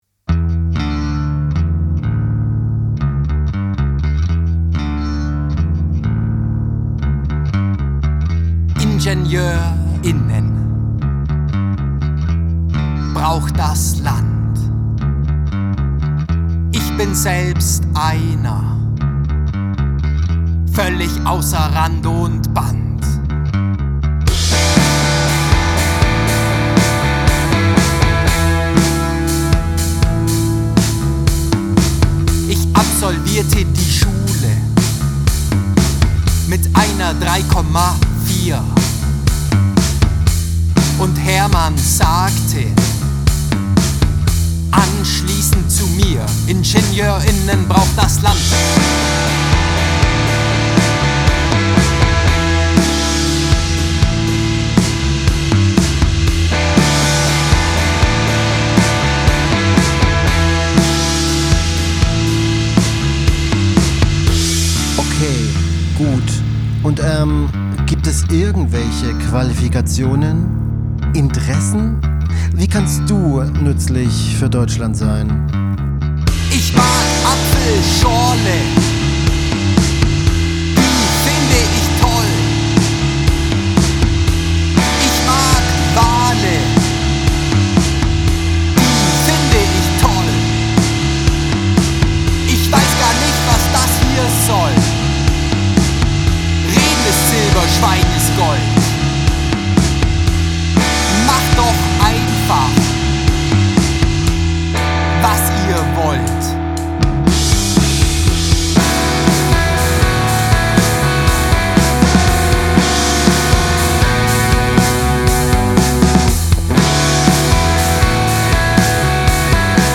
Gitarre
Bass
Schlagzeug
Es wird wird laut, verrückt, schrammlig.